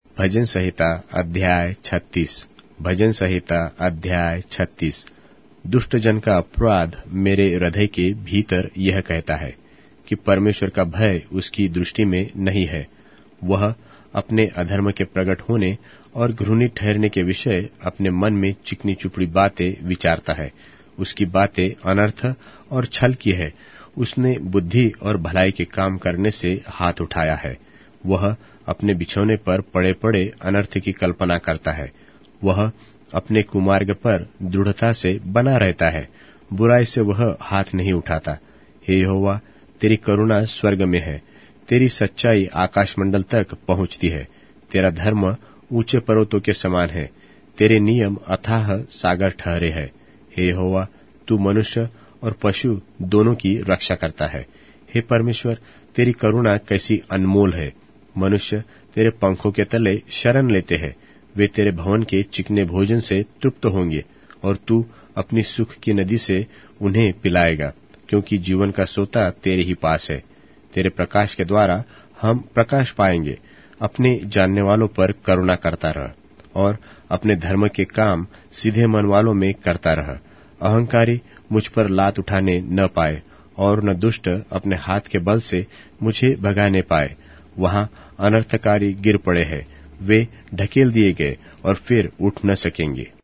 Hindi Audio Bible - Psalms 63 in Irvur bible version